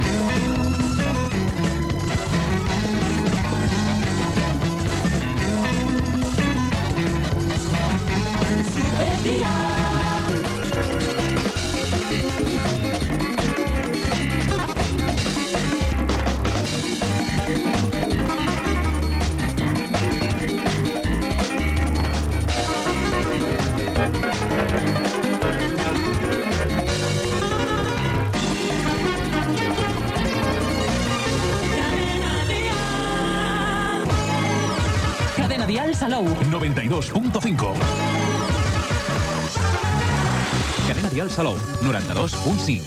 Indicatius de la cadena i de l'emissora